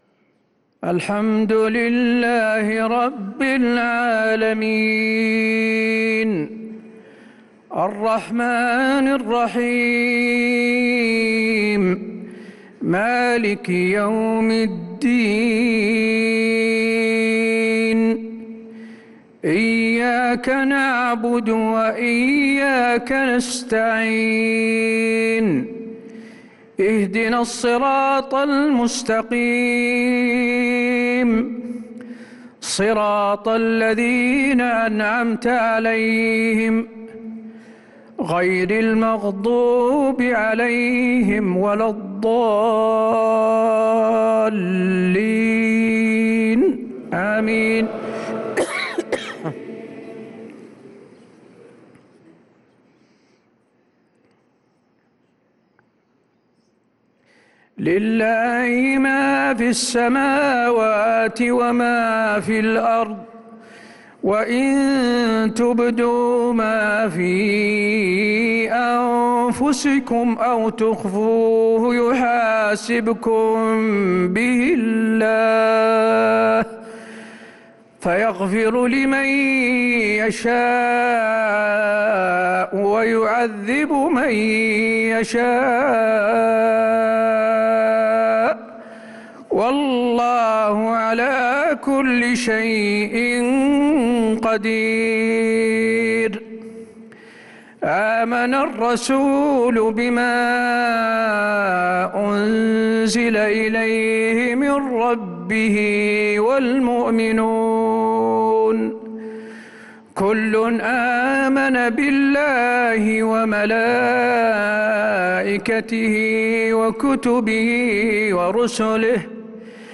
عشاء الأحد 9-9-1446هـ خواتيم سورتي البقرة (284-286) و الإنفطار (13-19) | Isha prayer from Surat al-Baqarah & al-Infitar 9-3-2025 > 1446 🕌 > الفروض - تلاوات الحرمين